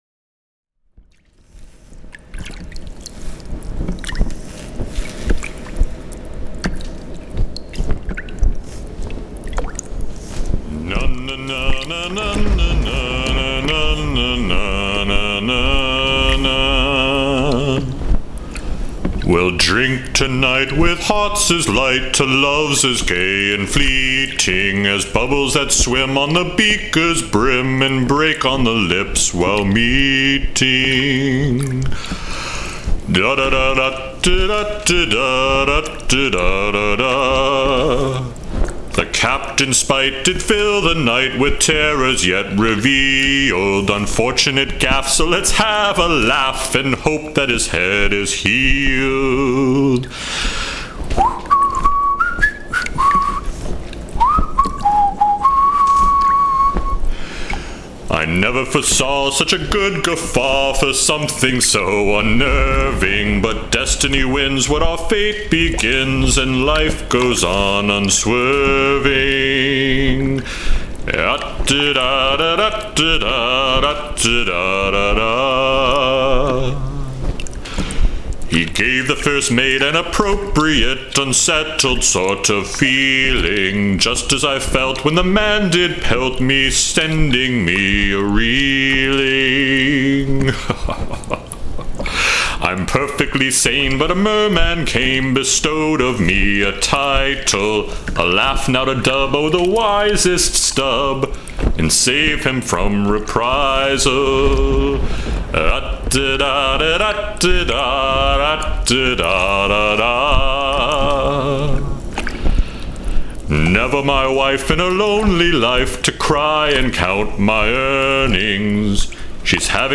This week’s song also features my first attempts at foley arts!*
*I cheated, however, and used a prerecorded thunder clap.